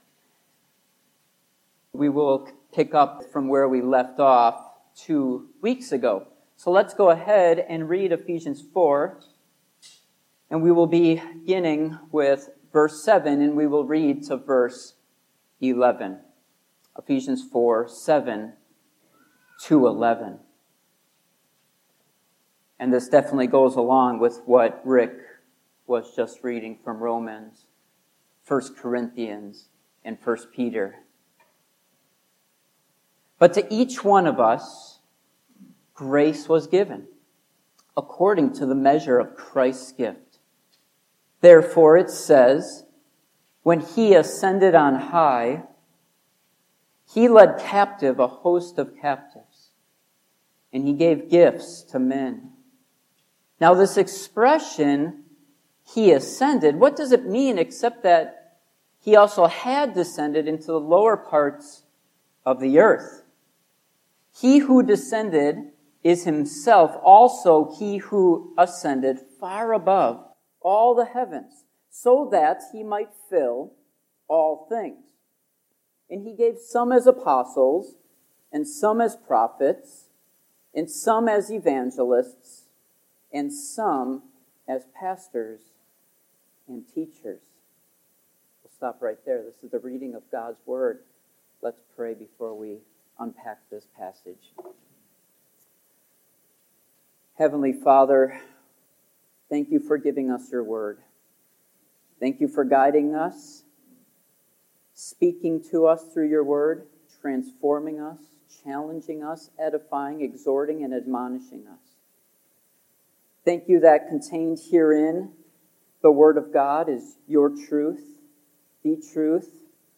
The Church Passage: Ephesians 4:7-11 Service Type: Morning Worship Topics